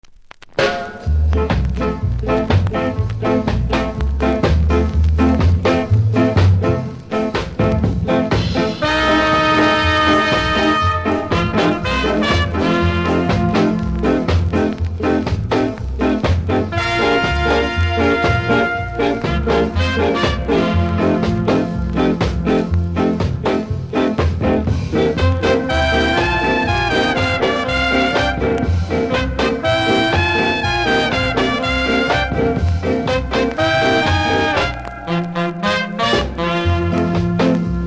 R&B
キズ多めでノイズもそれなりにありますので試聴で確認下さい。